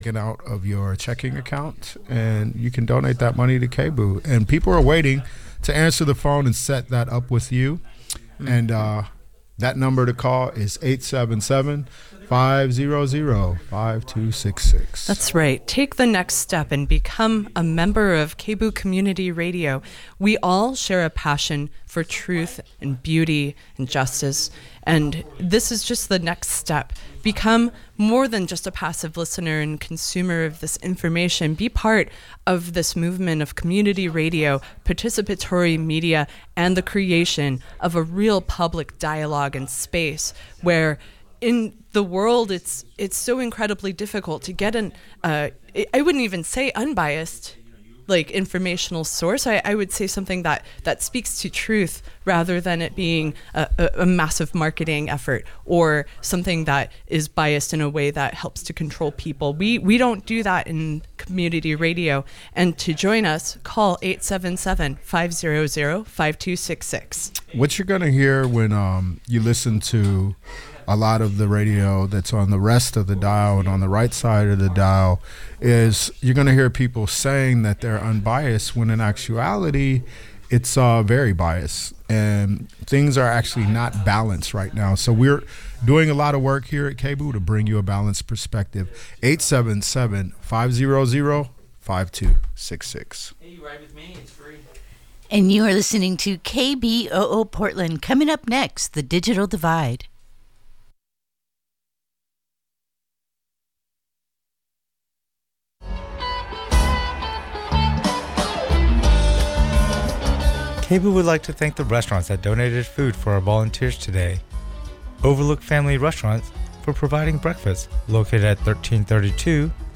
encore interview
Truthdig Editor in Chief, author, and journalist, Robert Scheer